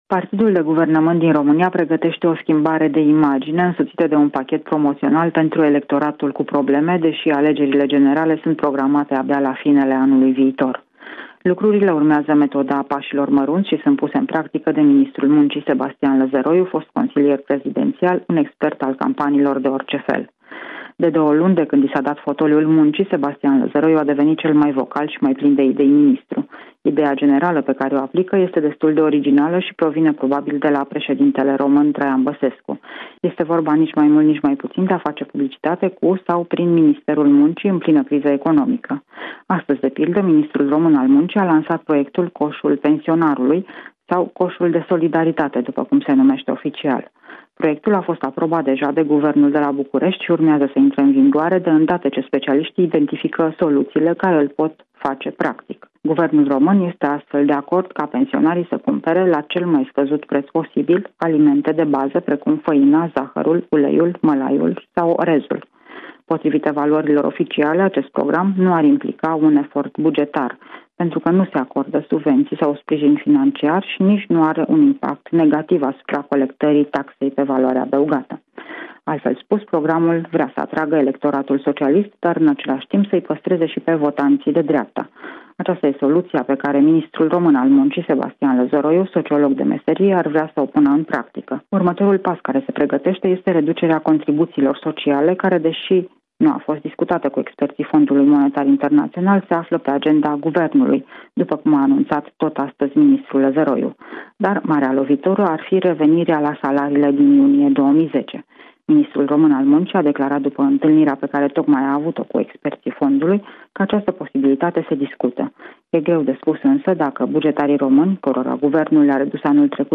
Corespondenţa zilei de la Bucureşti